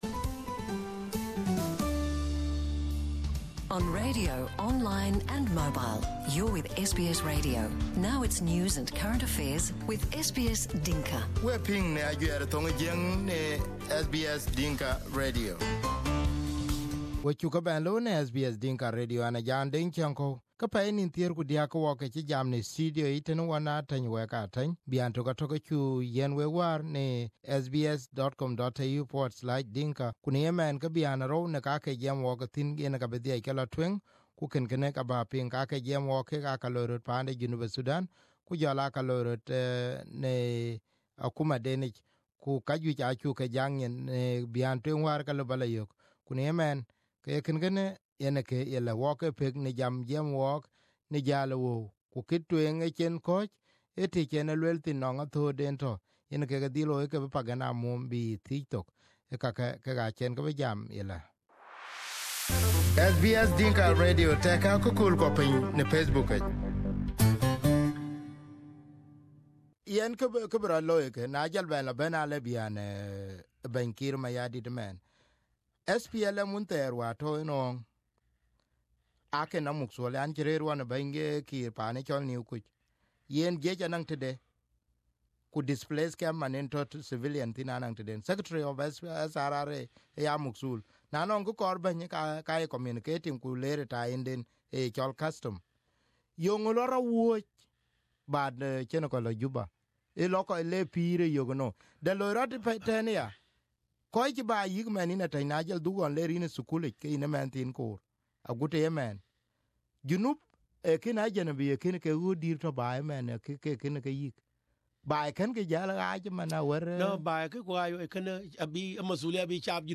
Here is the part two of the interview Ateny also talk about the challenges being face by the Government of South Sudan.